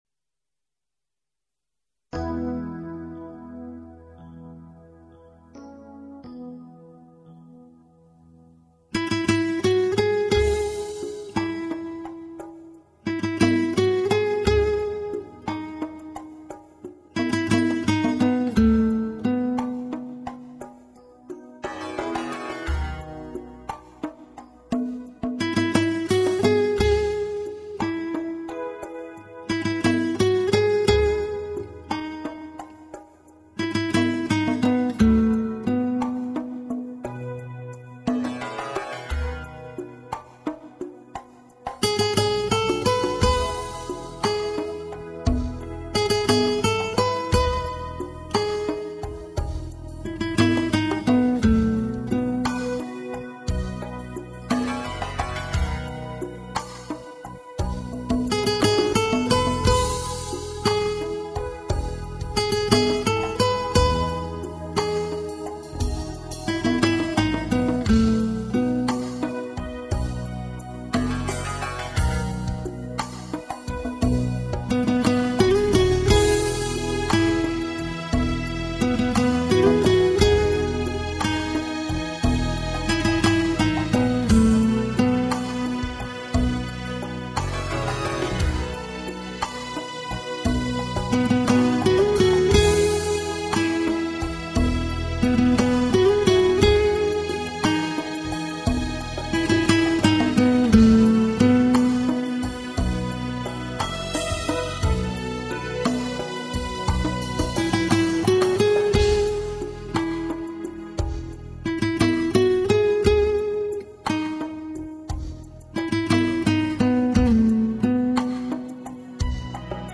0147-吉他名曲多拉内斯曲调.mp3